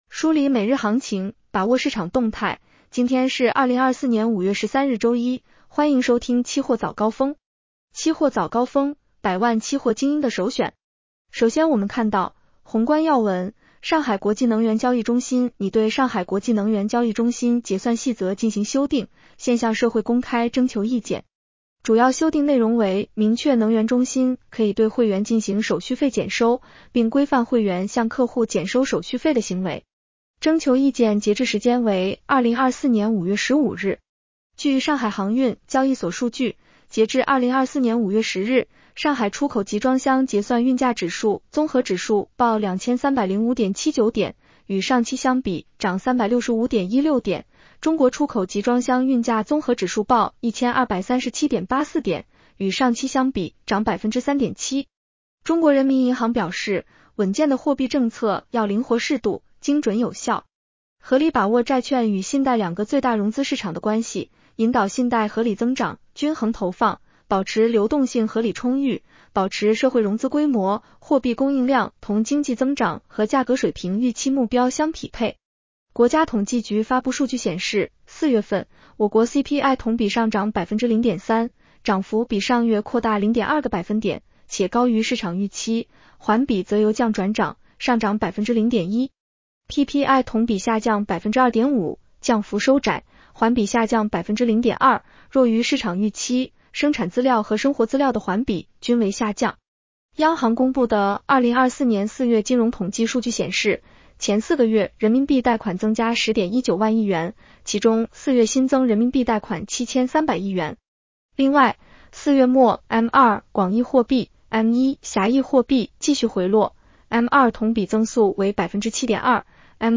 期货早高峰-音频版 女声普通话版 下载mp3 宏观要闻 1.上海国际能源交易中心拟对《上海国际能源交易中心结算细则》进行修订，现向社会公开征求意见。